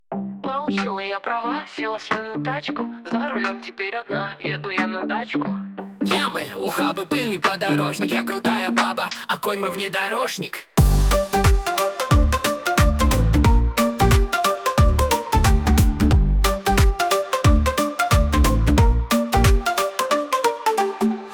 Шуточная песня про автоледи за рулем
Фрагмент варианта исполнения: